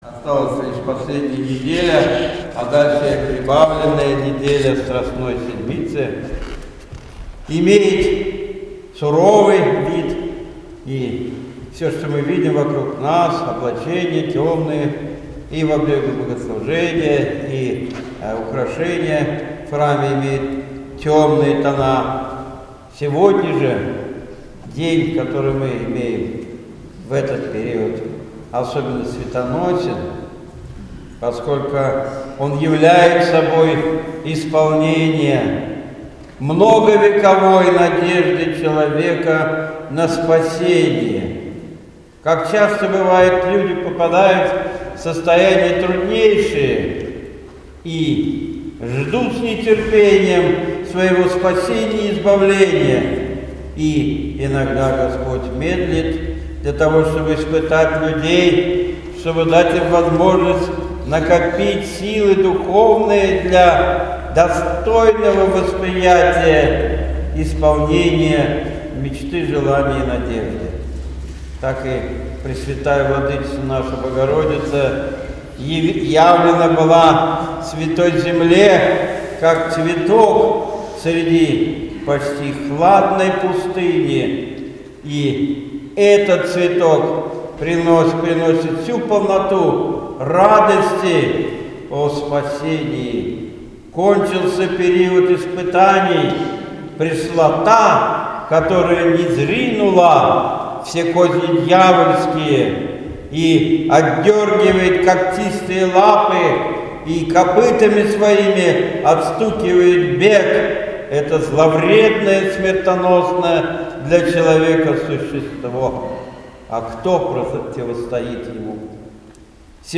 Наставление